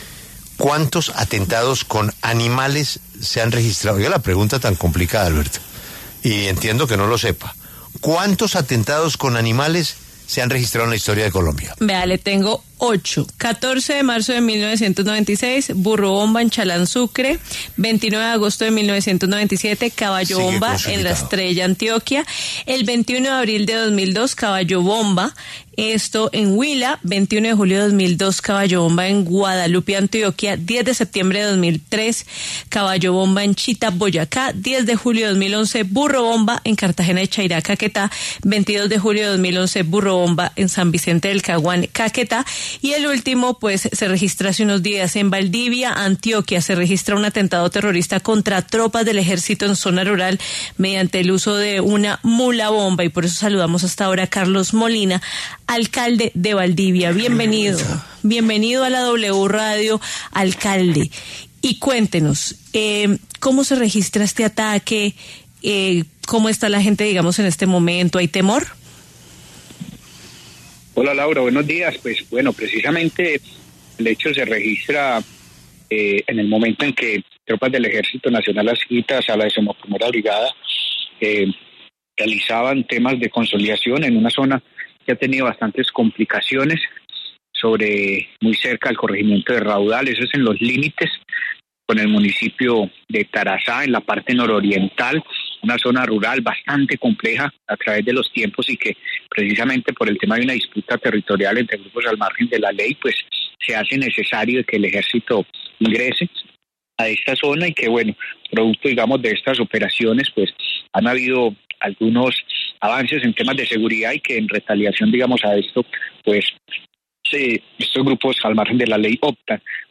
El alcalde de Valdivia, Antioquia, Carlos Molina, pasó por los micrófonos de La W, con Julio Sánchez Cristo, para hablar acerca de la tensa situación que se vive por cuando de las confrontaciones entre las disidencias de las Farc y el ELN en esa región del país, lo que obligó a que interviniera el Ejército Nacional.